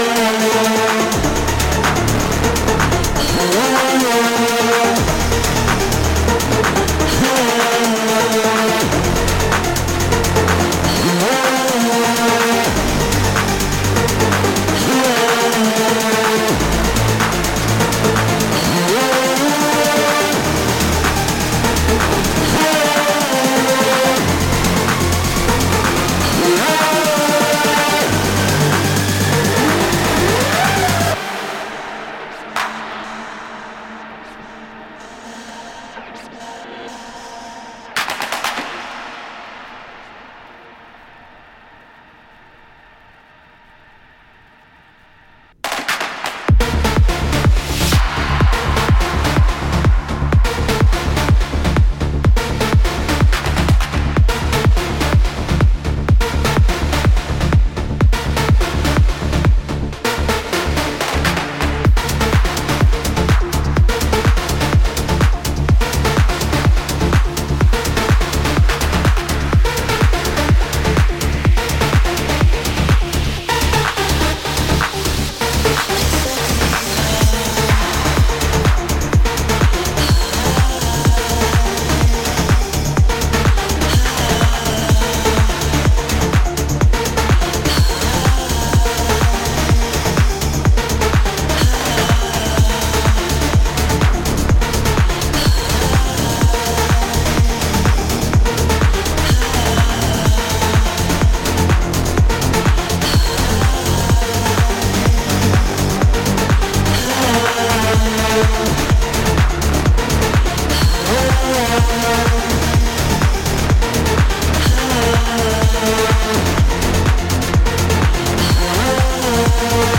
House/Tech House, Trance